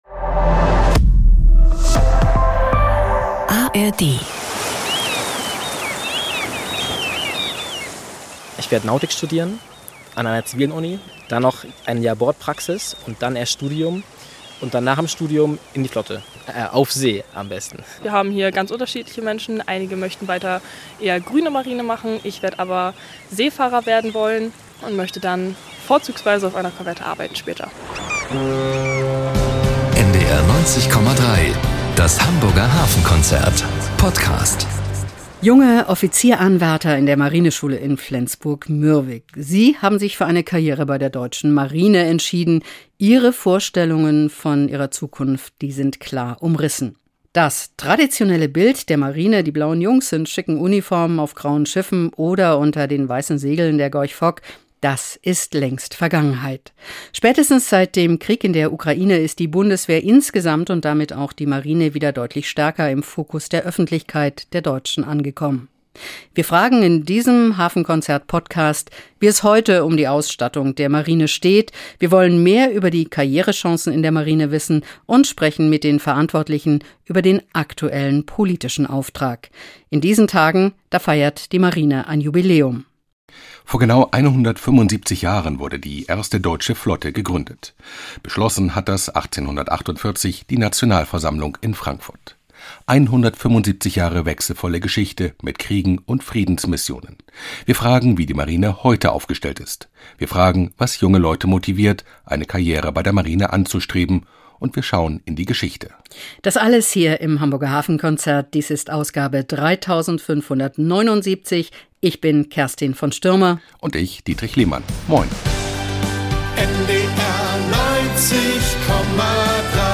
Die politischen Rahmenbedingungen im Land bestimmen fortan die Situation der Flotte. 175 Jahre nach Gründung gedenkt die Deutsche Marine jetzt dieses Jubiläums. Wie ist die Flotte heute aufgestellt und welchen Auftrag hat sie innerhalb der Bundeswehr? Junge Offizieranwärter berichten über ihre Karrierepläne und der Inspekteur der Marine spricht über die Herausforderungen heute.